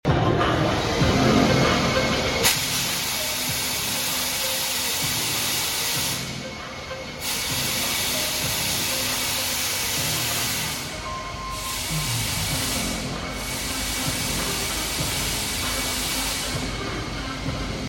MK-F02 X20 Fog Jet offers instant burst effects similar to a CO2 cannon but doesn’t require a CO2 tank. It uses specialized fog fluid, so it is the most convenient option for events.